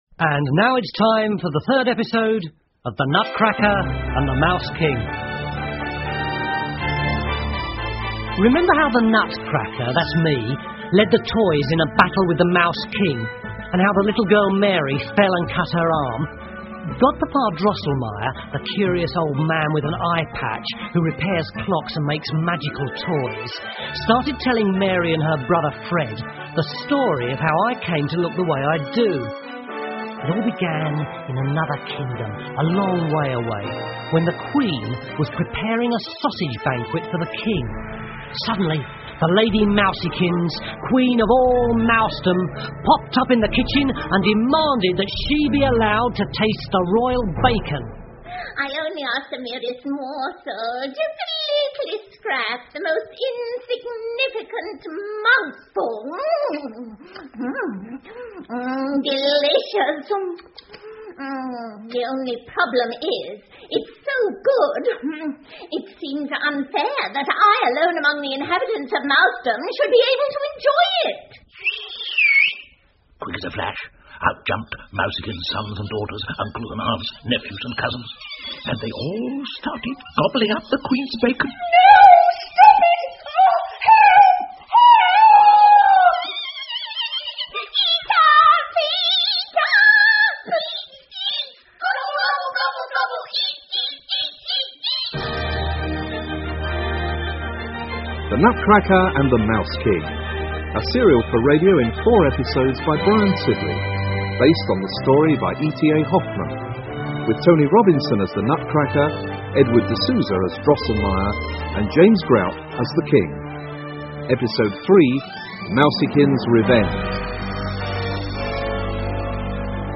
胡桃夹子和老鼠国王 The Nutcracker and the Mouse King 儿童广播剧 15 听力文件下载—在线英语听力室